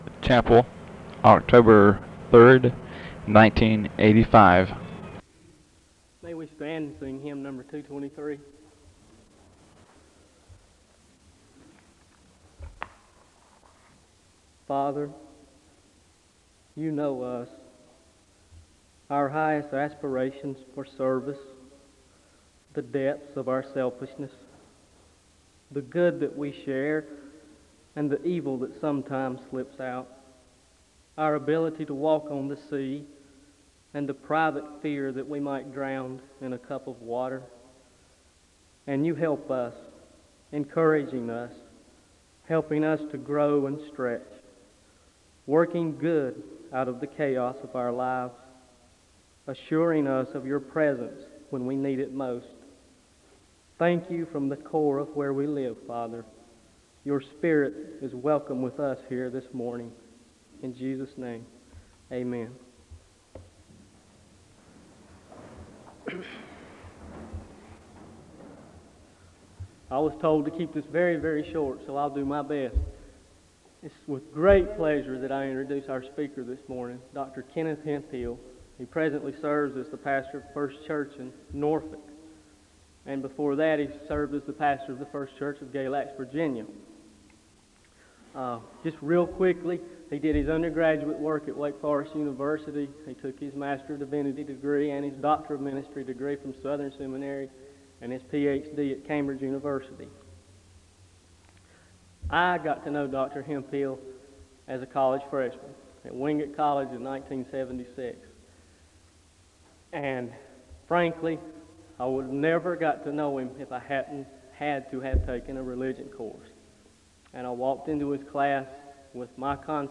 The service begins with a word of prayer (00:00-01:08).